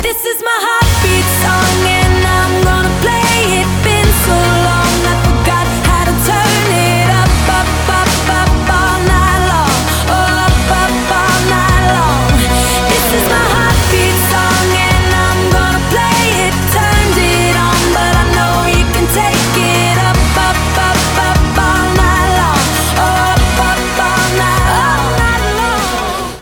• Качество: 192, Stereo
поп
громкие
vocal